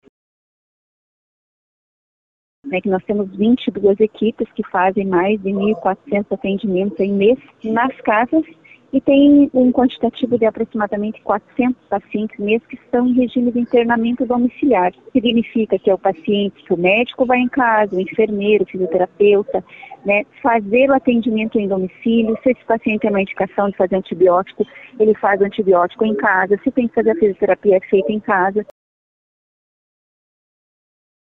A secretária municipal da saúde também enfatizou o atendimento domiciliar feito por 22 equipes, com atendimento de aproximadamente 1.400 pacientes, com parte deles que fica praticamente internada em casa.